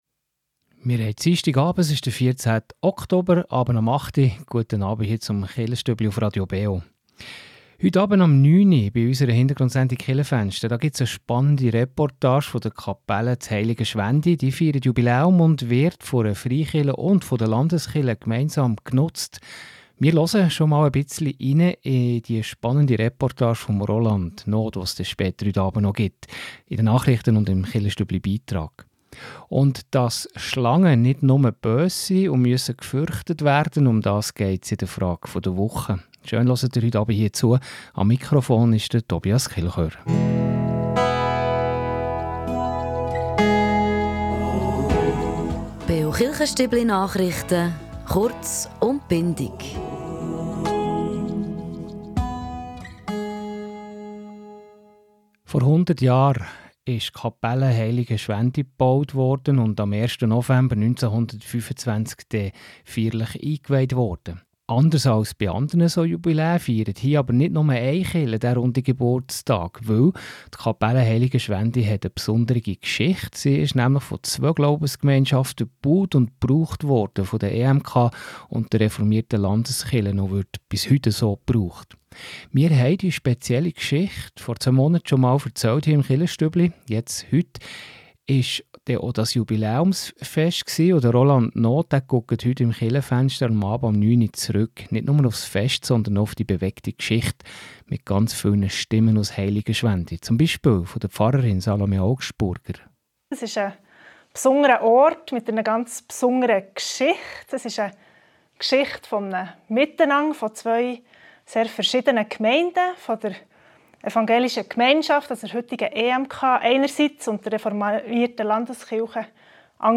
Die Kapelle Heiligenschwendi wurde 100 jährig. Im Chilchestübli hier geben wir schon einen kleinen Einblick auf die ganze Sendung mit der Reportage vom Fest.